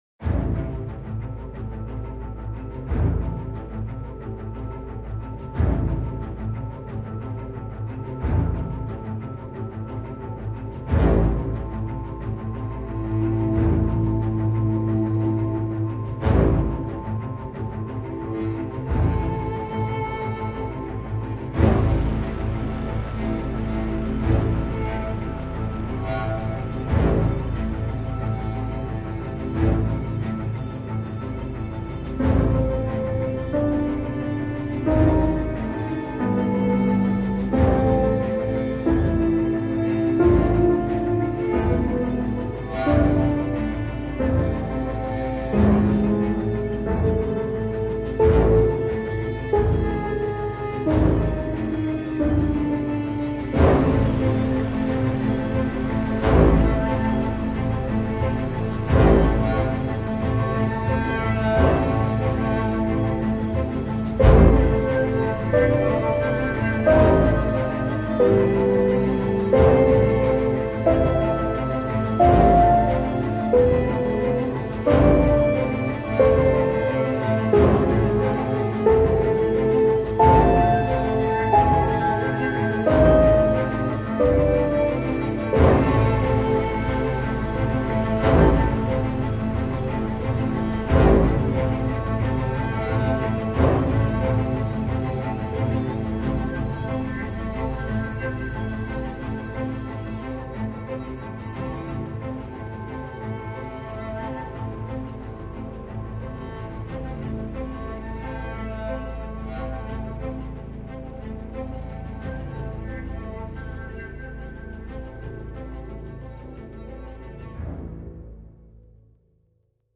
piano melody